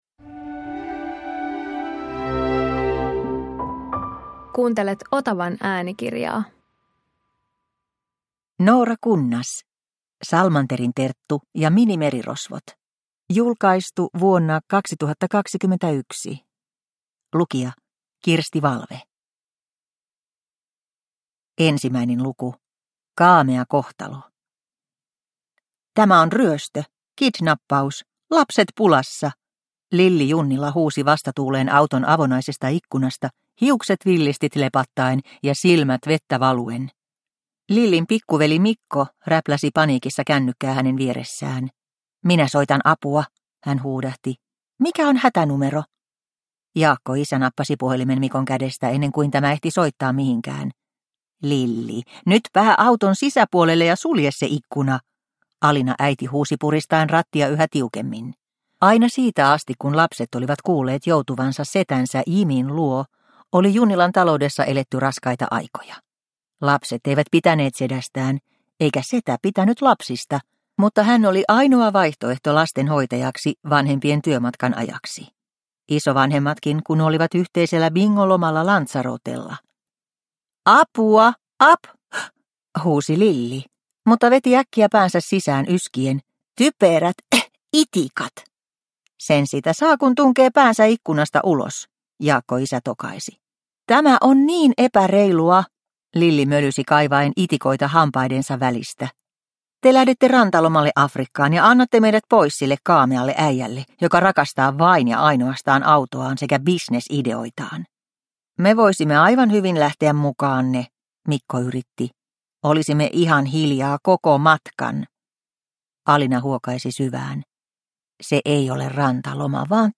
Salmanterin Terttu ja minimerirosvot – Ljudbok – Laddas ner